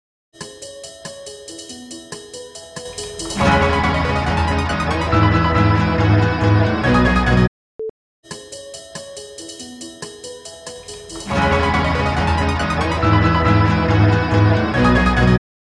The steep attacks can be reduced manually using audacity’s envelope tool …